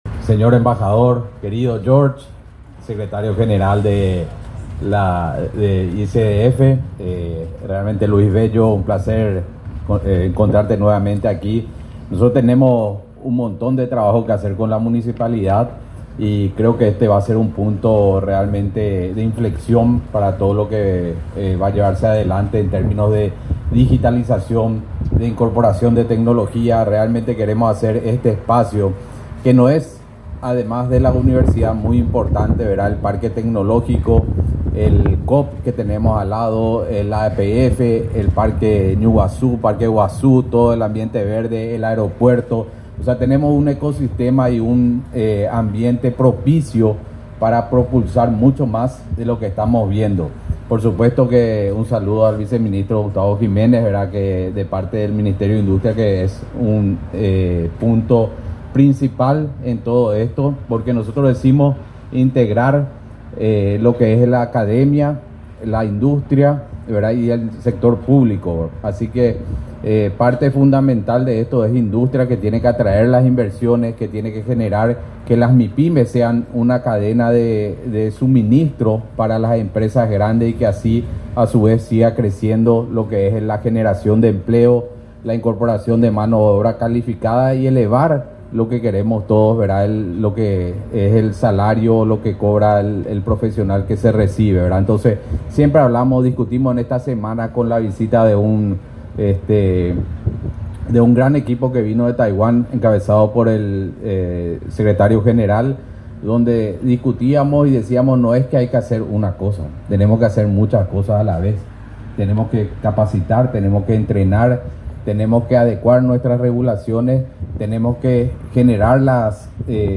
Durante la ceremonia, el ministro de Tecnologías de la Información y Comunicación, Gustavo Villate, destacó la relevancia del proyecto dentro del ecosistema tecnológico y académico que se desarrolla en la zona.